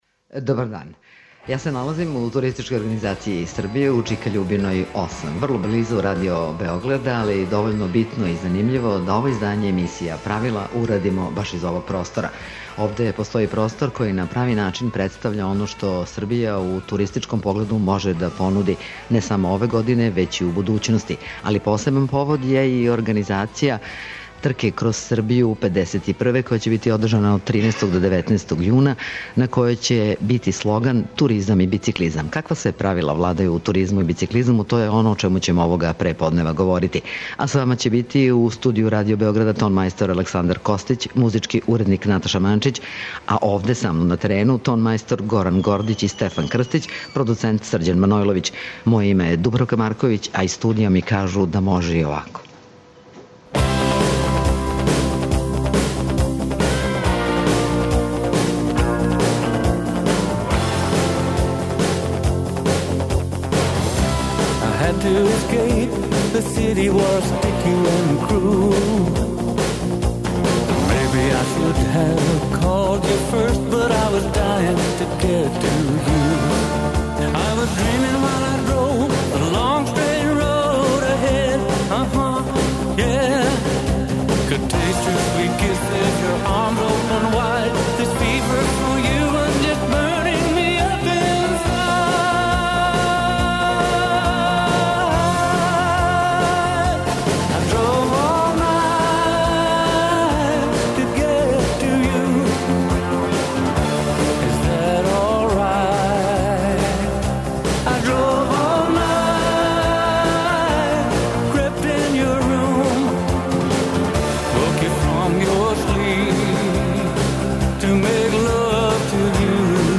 Туризам - бициклизам могуће правило за будућност Србије? Емисију реализијемо из просторија Туристичке организације Србије у Београду, а причамо о 51. Међународној бициклистичкој трци кроз Србију која ће бити одржана од 13.-19. јуна.